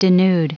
Prononciation du mot denude en anglais (fichier audio)
Prononciation du mot : denude